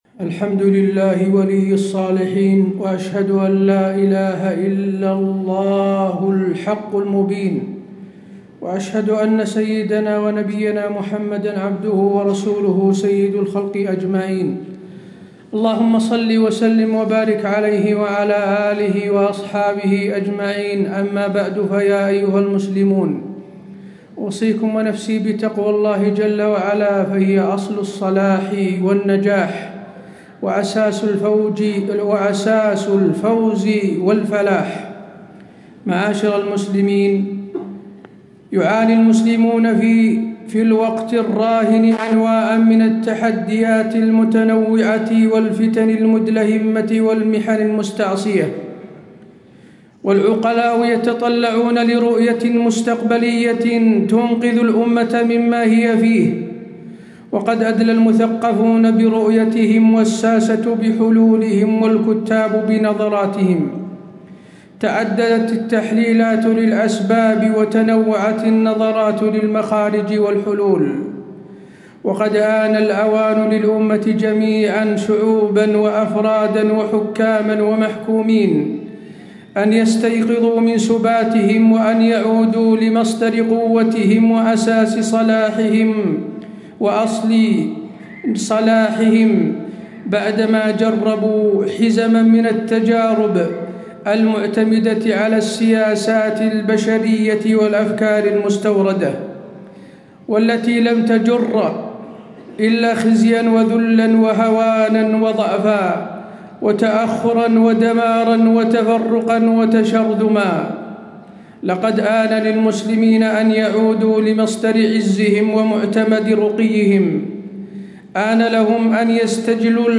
تاريخ النشر ٤ شعبان ١٤٣٦ هـ المكان: المسجد النبوي الشيخ: فضيلة الشيخ د. حسين بن عبدالعزيز آل الشيخ فضيلة الشيخ د. حسين بن عبدالعزيز آل الشيخ الطريق إلى النصر والتمكين